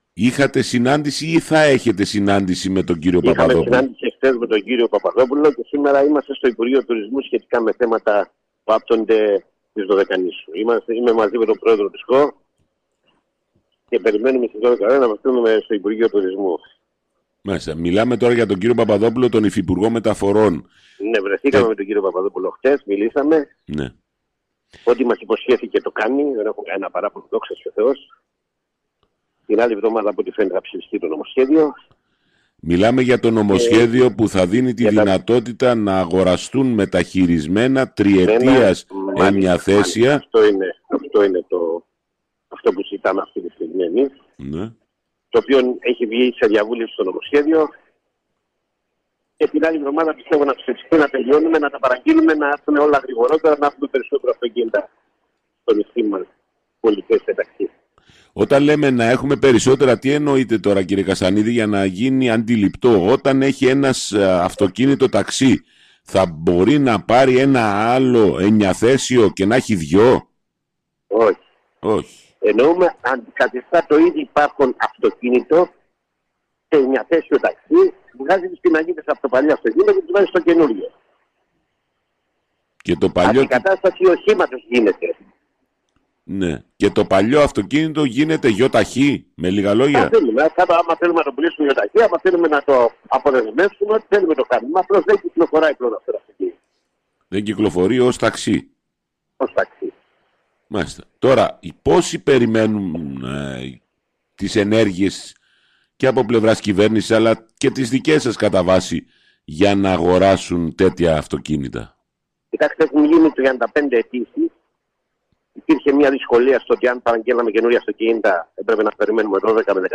Μιλώντας σήμερα στον  SKY